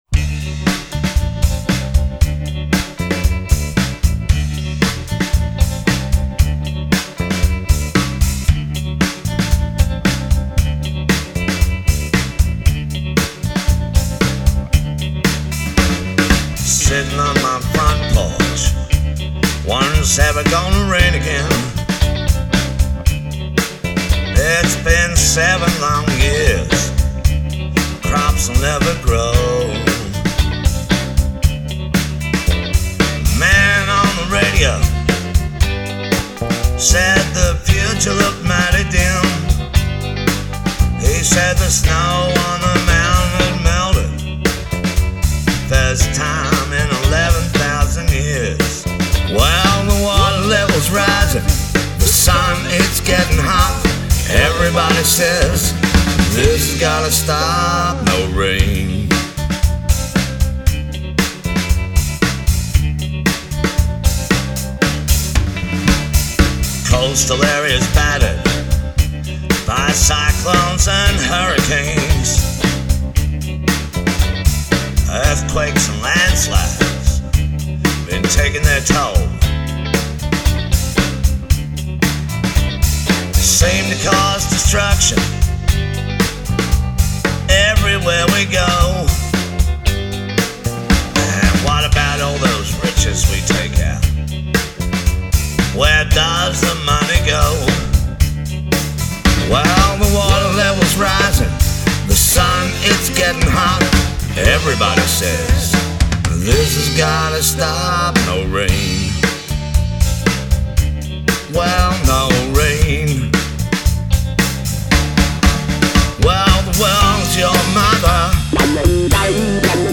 Country and Blues